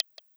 GenericButton4.wav